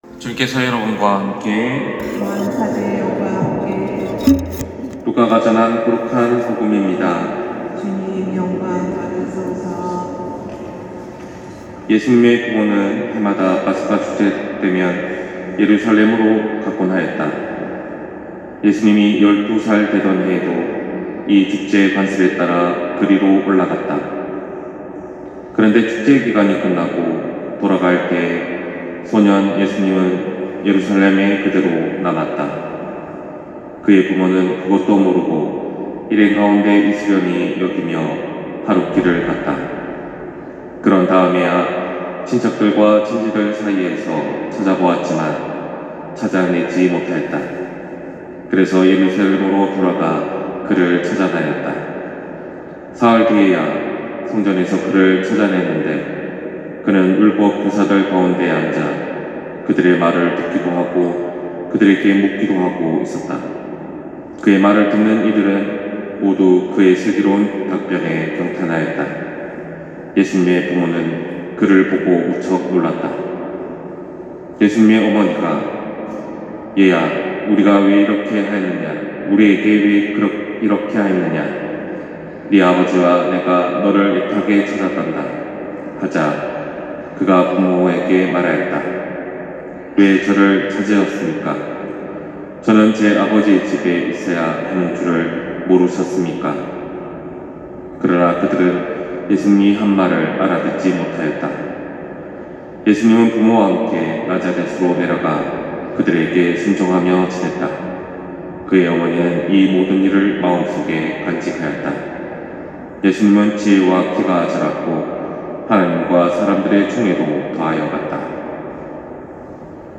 241228신부님 강론말씀